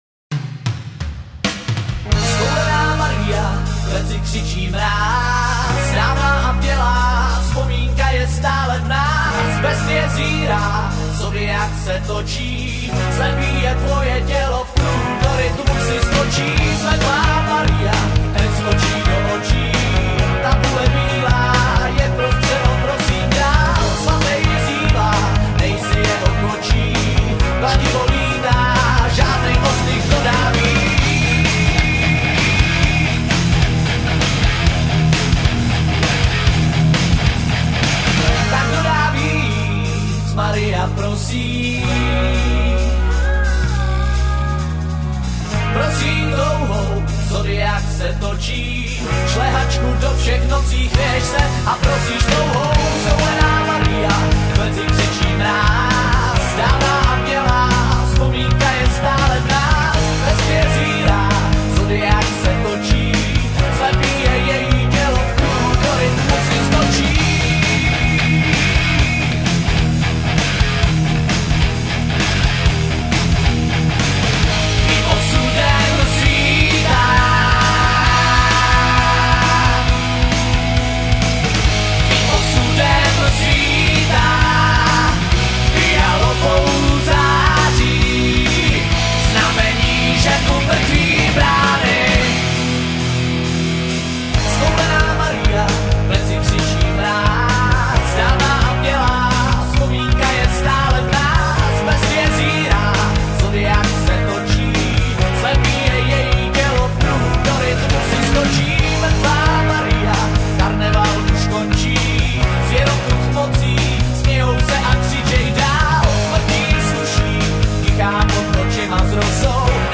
kytara